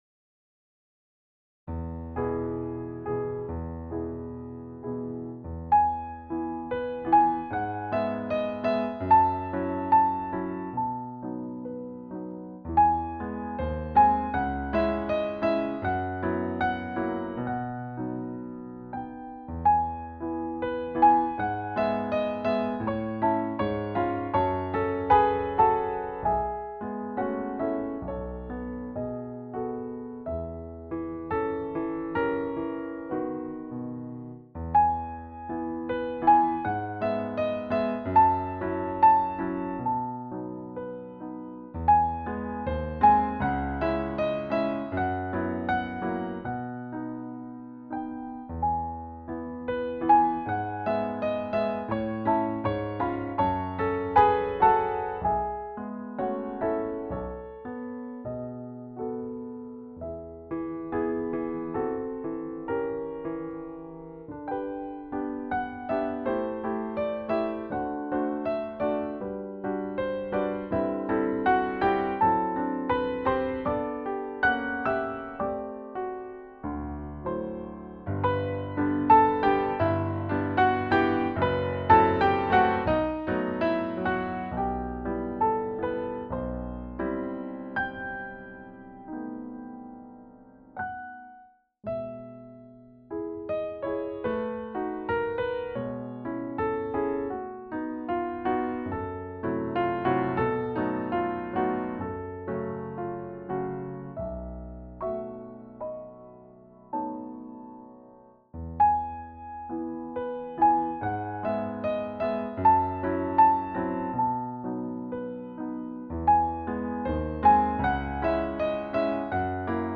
– Solo Piano –